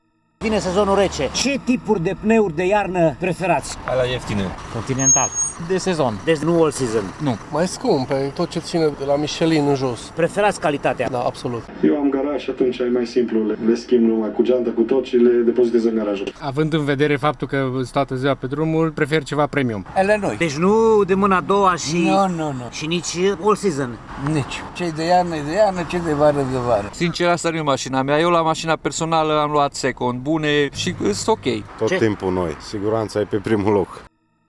Unii șoferi preferă cauciucurile de iarnă mai ieftine, alții spun că siguranța în trafic e prioritară și le cumpără pe cela mai scumpe, premium: